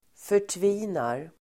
Uttal: [för_tv'i:nar]